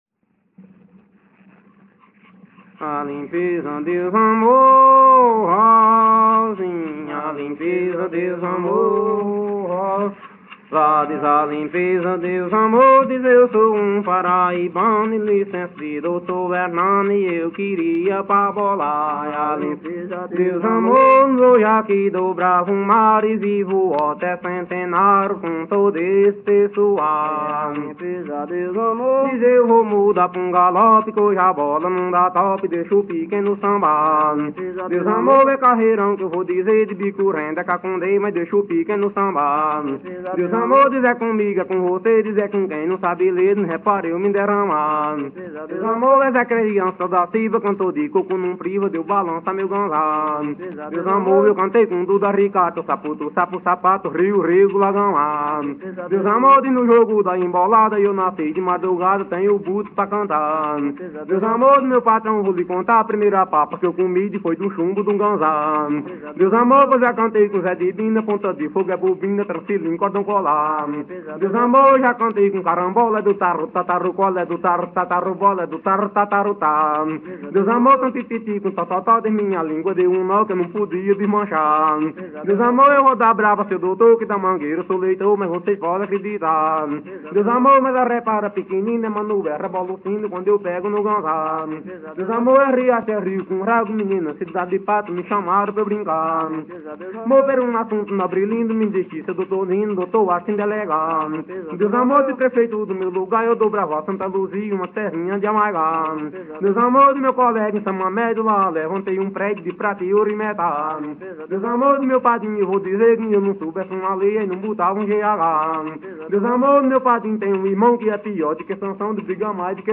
Coco embolada/galope – “”Adeus, adeus amor”” - Acervos - Centro Cultural São Paulo